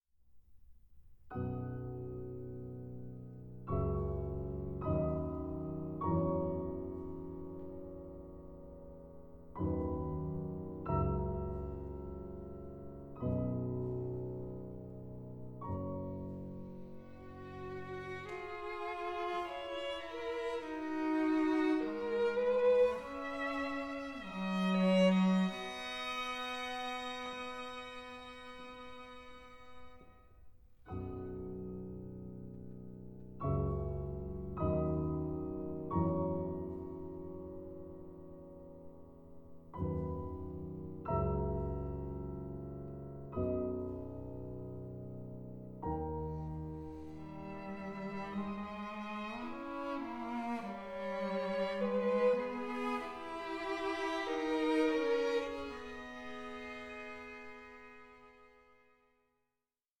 Adagio 08:42